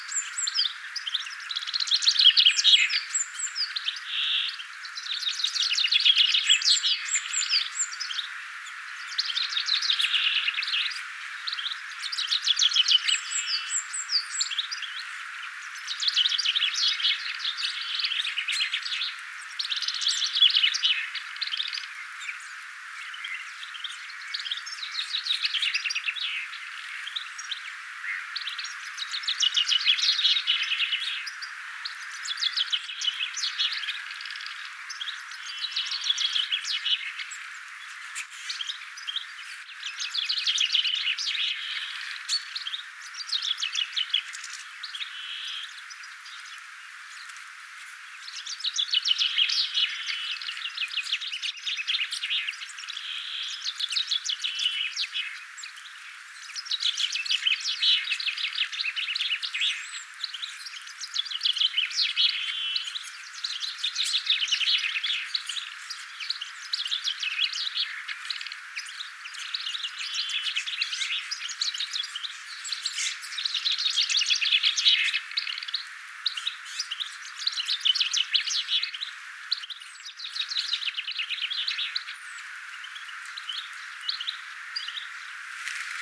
tikli ääntelee järripeipot laulavat
tikli_aantelee_peipot_ja_jarripeipot_laulavat.mp3